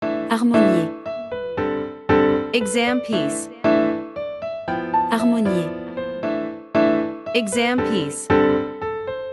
Professional-level Piano Exam Practice Materials.
• Vocal metronome and beats counting